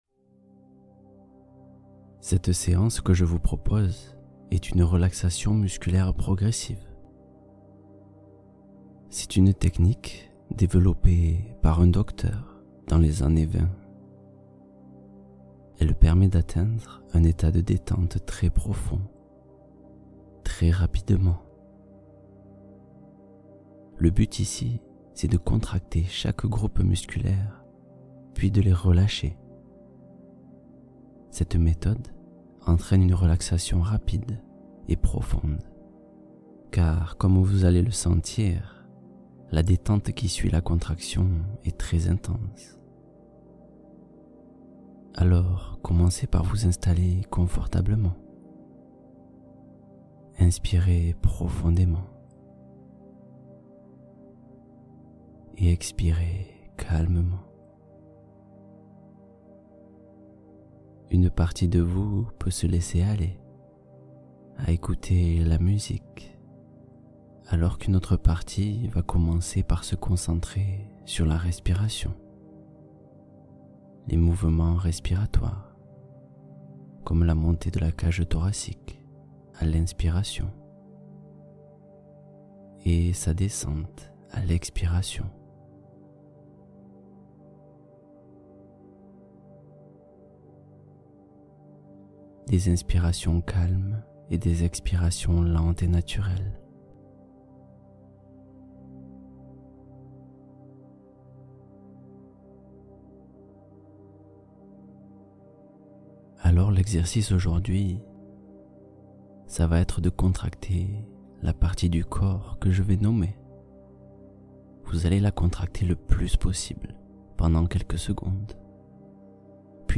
Traverser l’anxiété — Méditation guidée pour accueillir les tempêtes internes